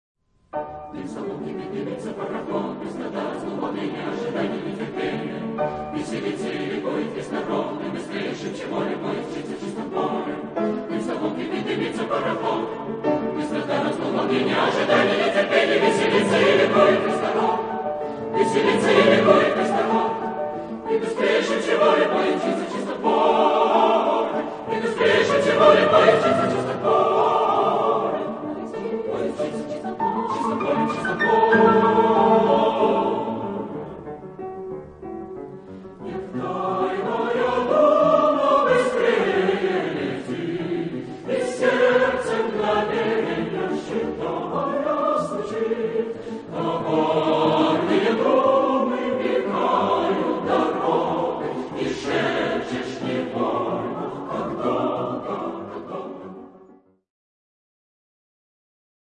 SATB (4 voices mixed) ; Choral score with piano.
Partsong.
Tonality: D major